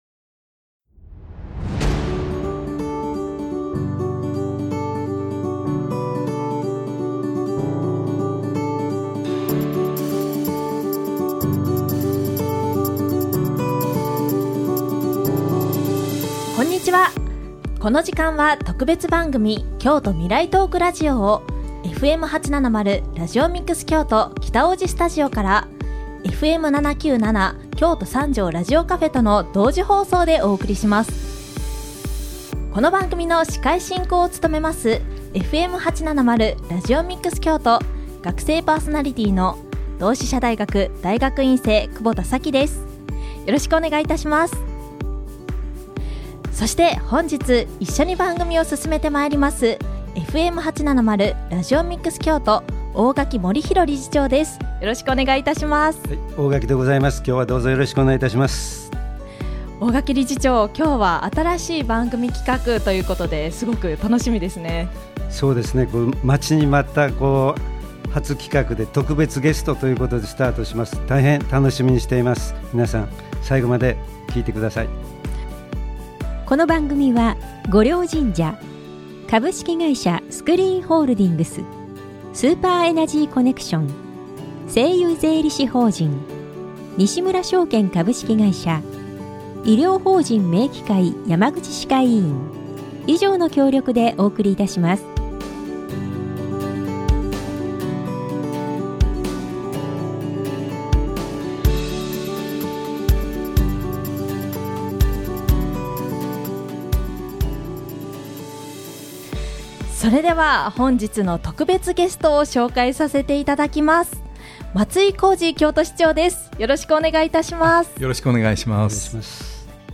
京都の文化人や経済人のみなさんが様々な観点から未来の京都について自由に語り合い、コミュニティからコミュニティへ様々な話題や情報を提供、情報発信するトーク番組。